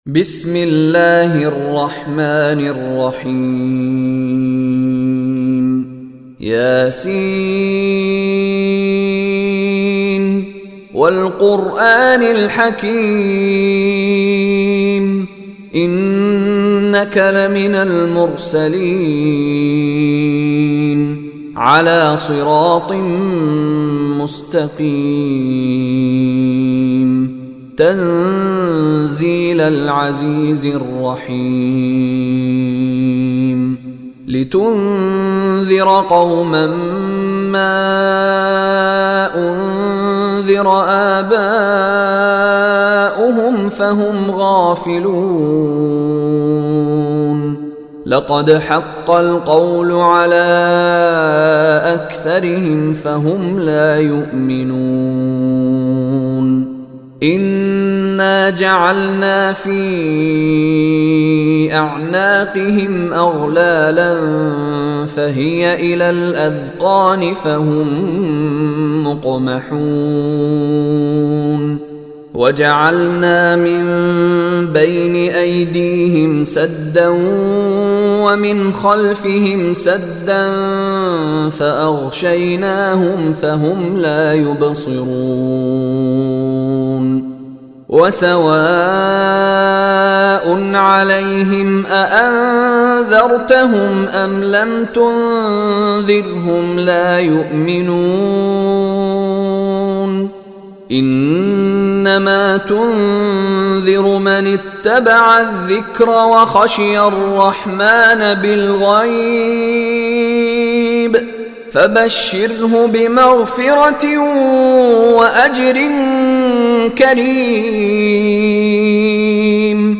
Surat Yseen Receiter Meshary Rashed
Quran recitations